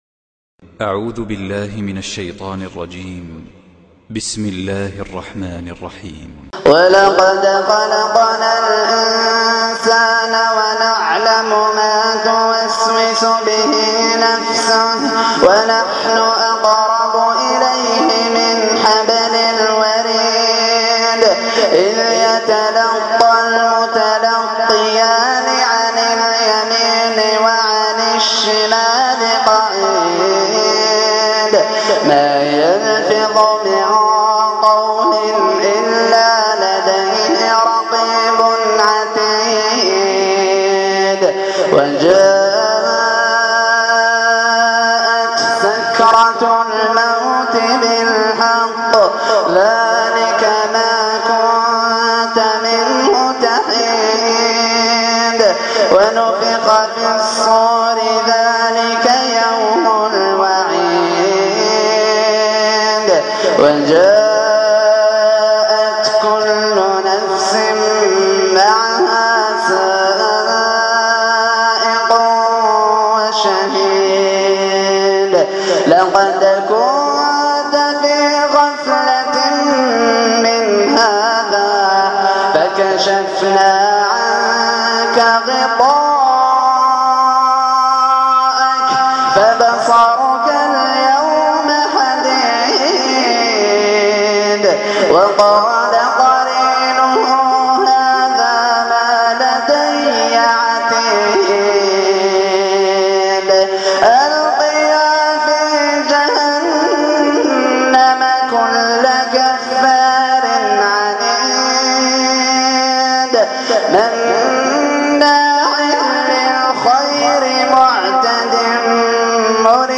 تلاوة خاشعة ومؤثرة تدمع لها العين من صلاة التراويح